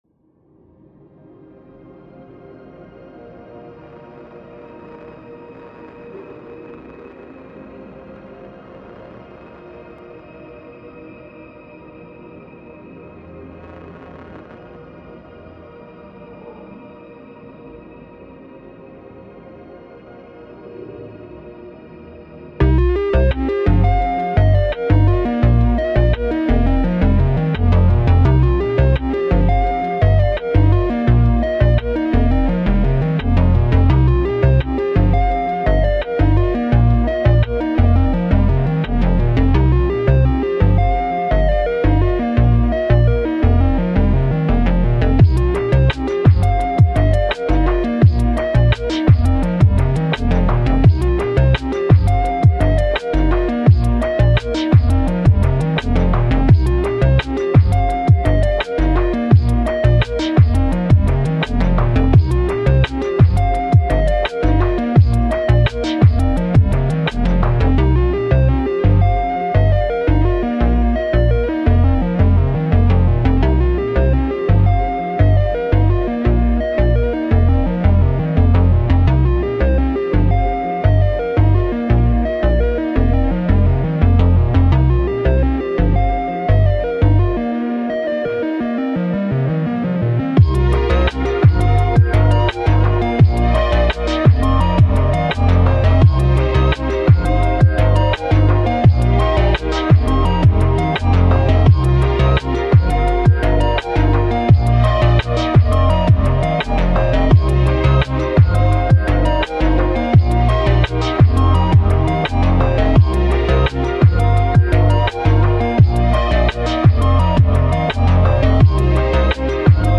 Index of /Music/recovered/vaporwave/